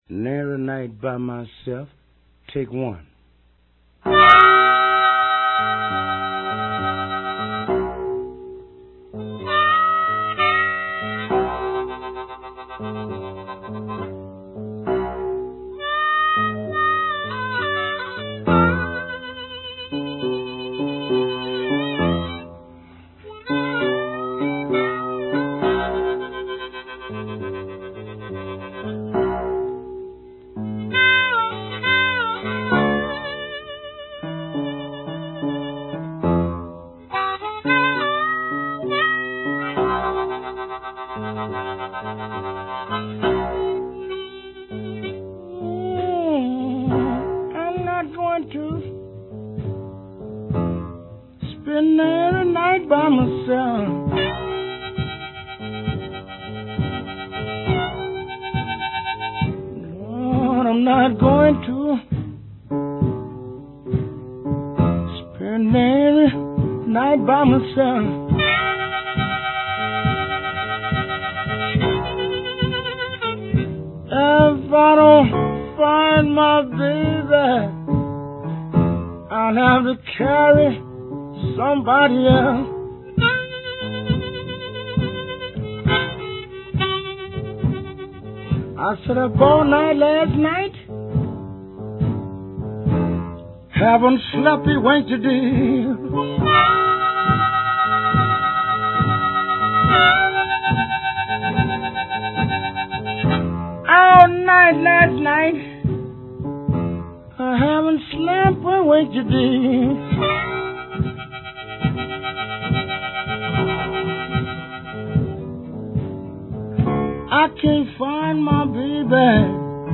Chicago Blues